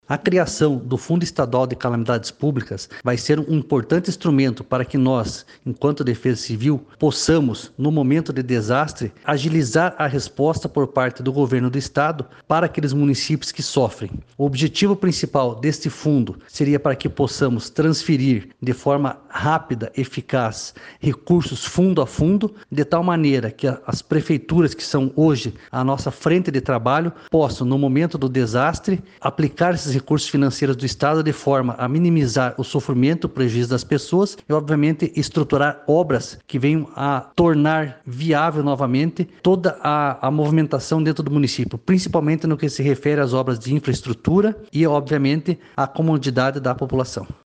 Sonora do coordenador estadual da Defesa Civil, coronel Fernando Schunig, sobre a criação do Fundo Estadual de Calamidades Públicas